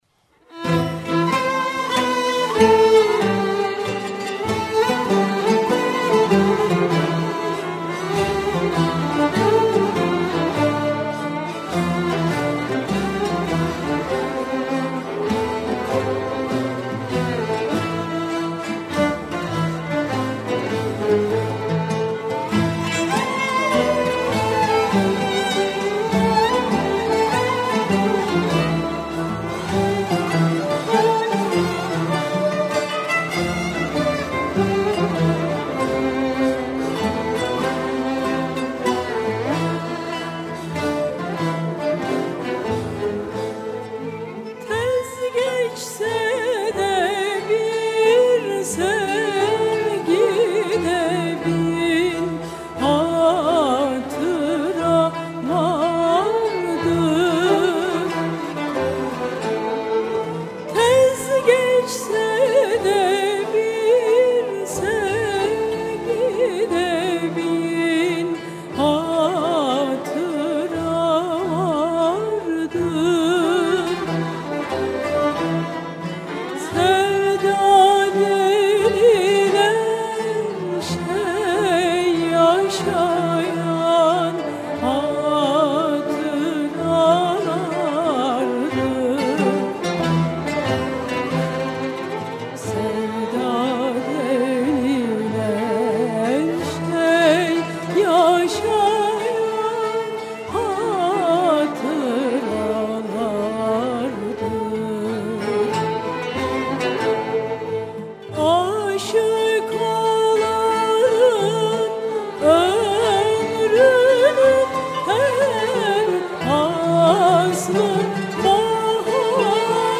Eser: Tez geçse de bir sevgide bin hatıra vardır Bestekâr: Sadettin Kaynak Güfte Sâhibi: Necdet Atılgan Makam: Nihavend Form: Şarkı Usûl: Yürük Semai...
Sanat Müziği